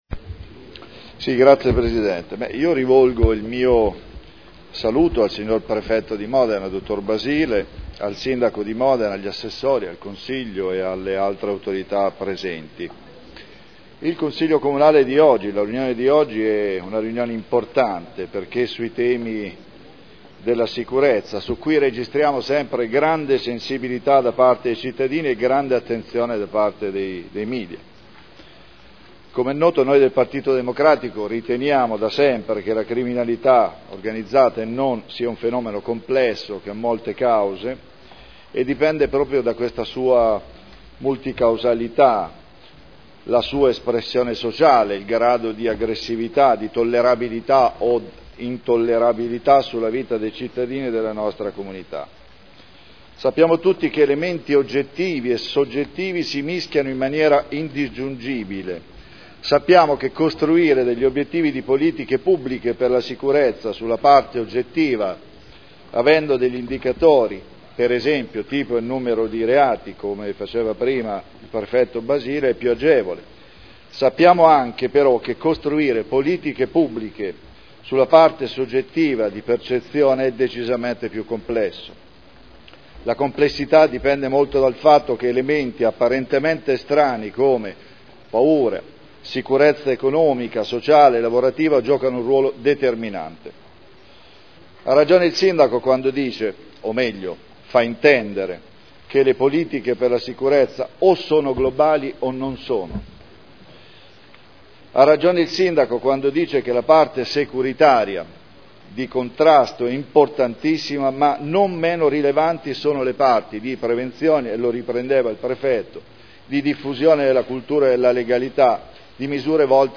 Seduta del 17/05/2012 "Patto per Modena sicura". Dibattito